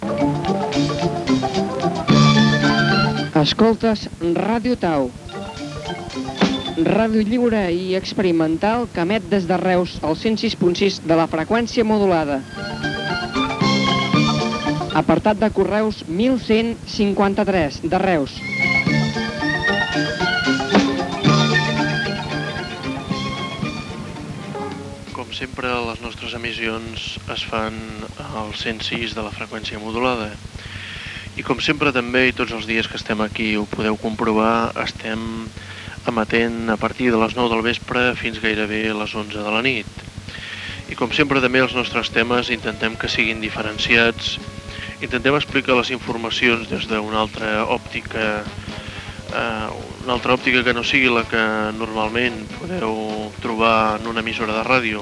9e97f2634c354ef04ac63a76241b5476ccf161a4.mp3 Títol Ràdio Tau Emissora Ràdio Tau Titularitat Tercer sector Tercer sector Lliure Descripció Identificació de l'emissora i objectius.